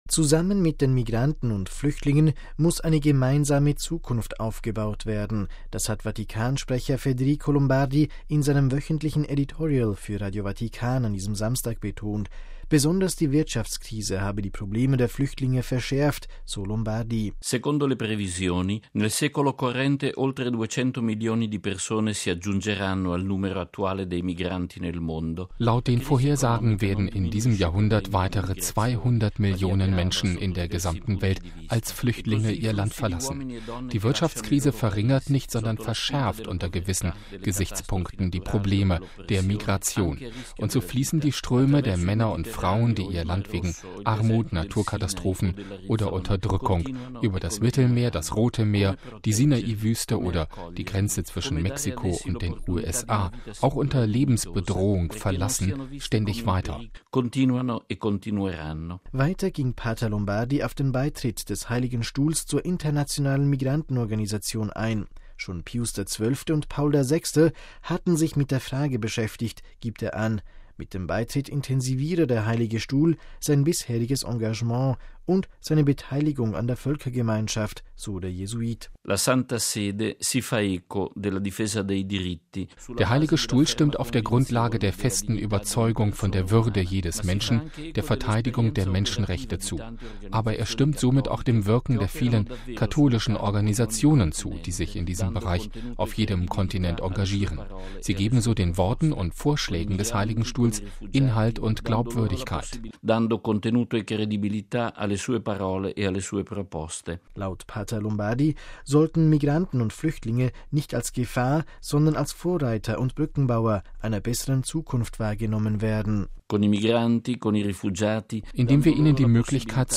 MP3 Zusammen mit den Migranten und Flüchtlingen muss eine gemeinsame Zukunft aufgebaut werden. Das hat Vatikansprecher Pater Federico Lombardi in seinem wöchentlichen Editorial für Radio Vatikan an diesem Samstag betont.